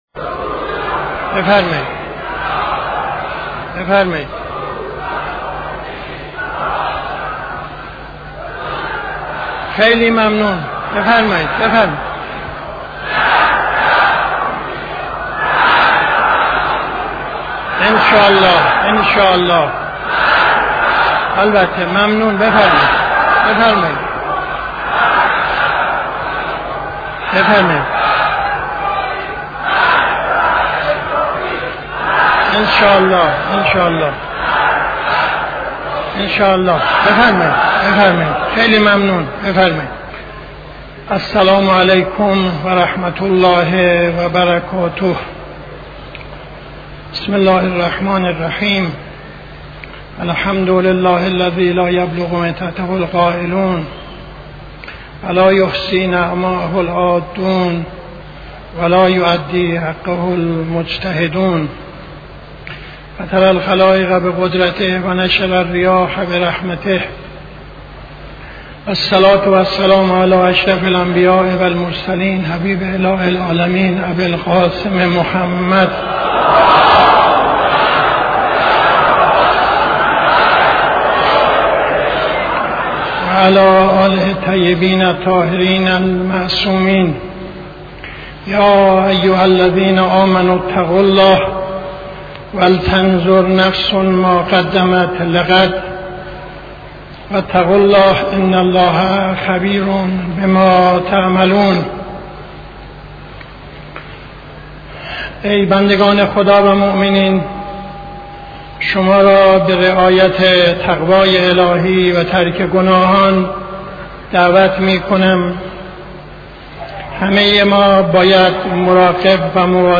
خطبه اول نماز جمعه 06-05-74